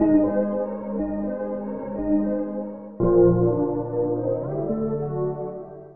描述：Cd cut , Riped from cd , and cutted with Sawcutter 1,2 or others , processed with FX , normalised.
声道立体声